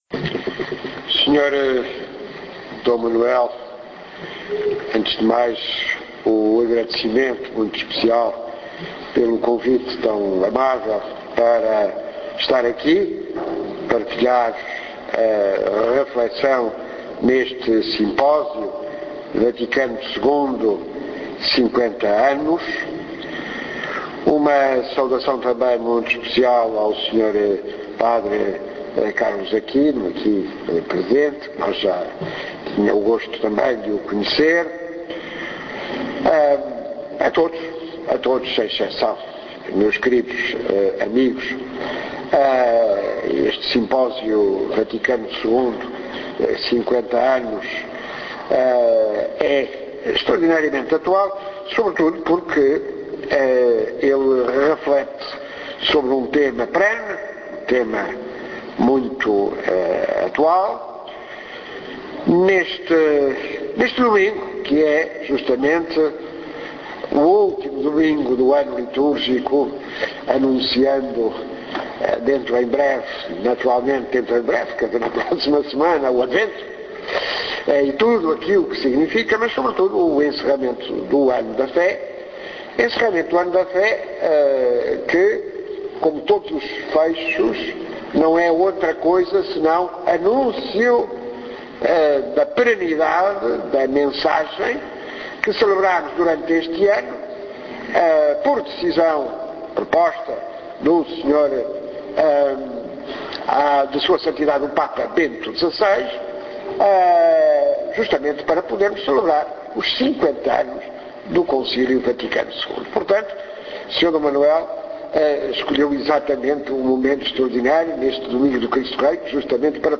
Conferência de Guilherme d’Oliveira Martins.
Conferencia_guilherme_oliveira_martins2.mp3